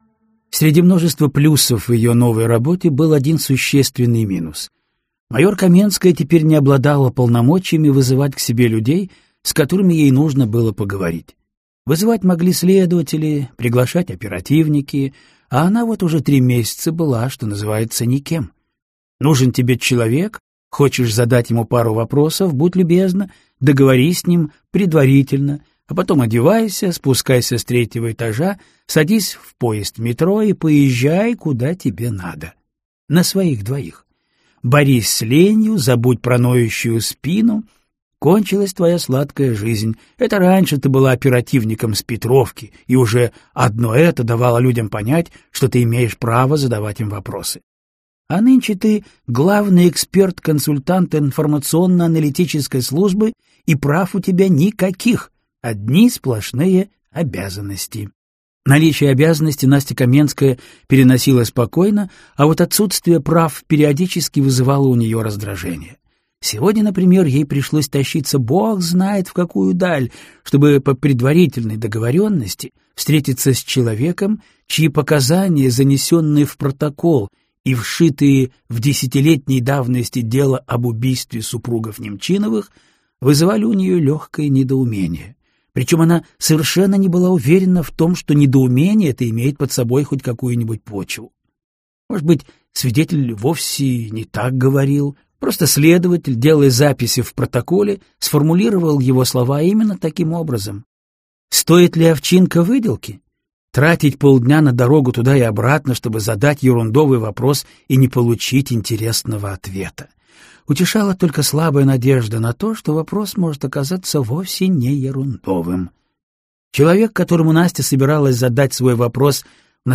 Аудиокнига Реквием | Библиотека аудиокниг